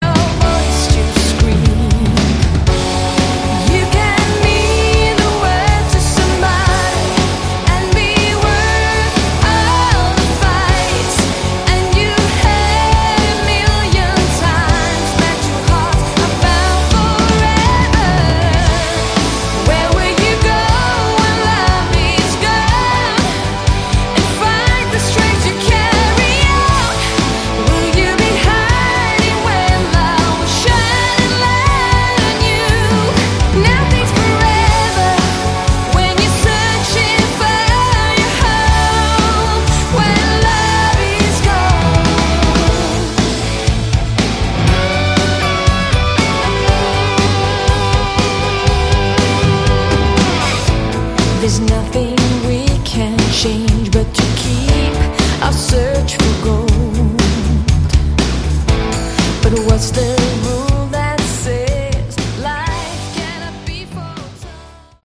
Category: Melodic Rock
lead vocals
keyboards, percussion, backing vocals
guitars
nylon guitar
bass
drums